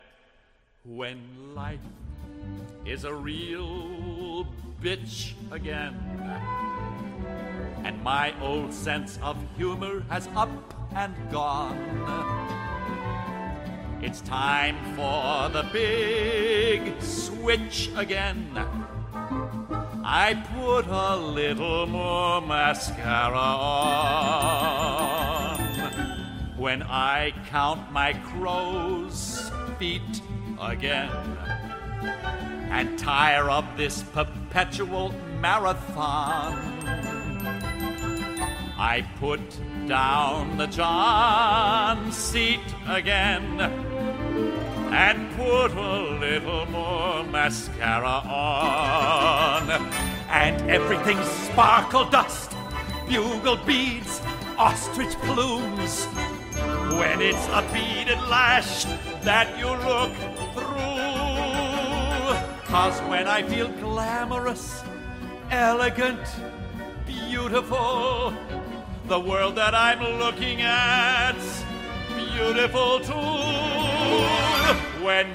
Low voices – male